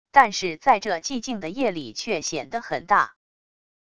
但是在这寂静的夜里却显得很大wav音频生成系统WAV Audio Player